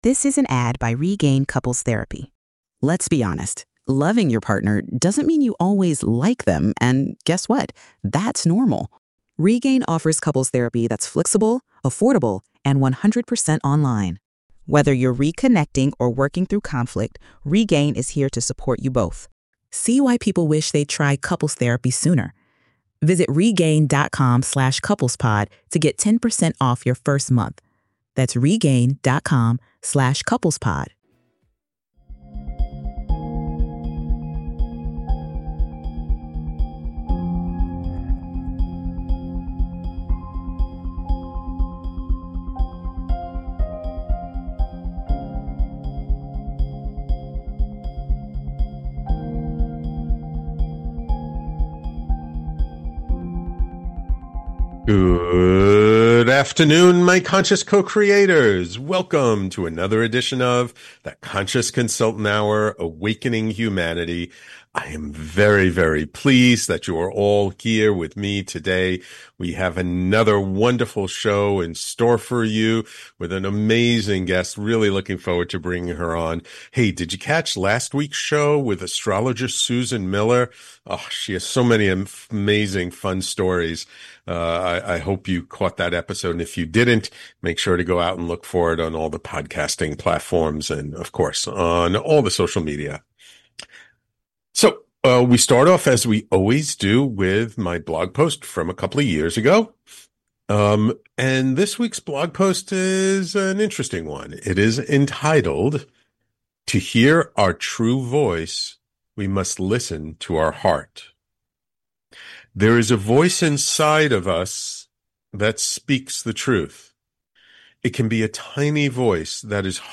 Tune in for one hour of consciousness raising talk.